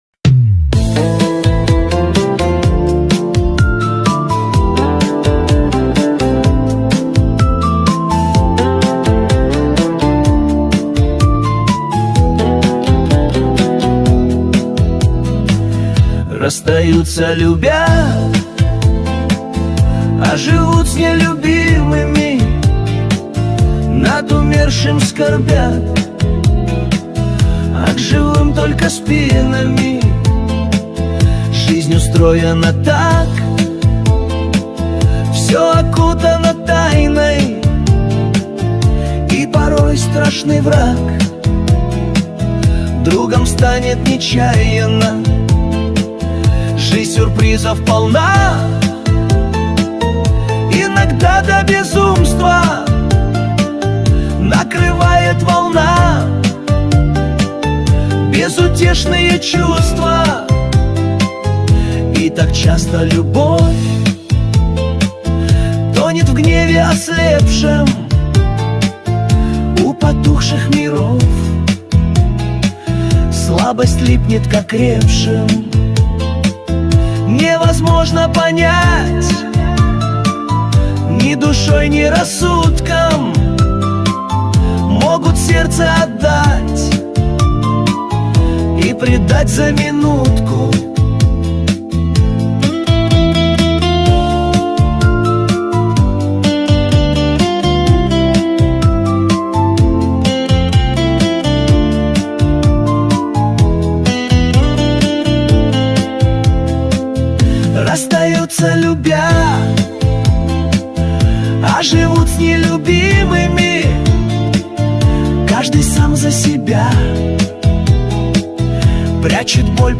Качество: 251 kbps, stereo
Стихи, Нейросеть Песни 2025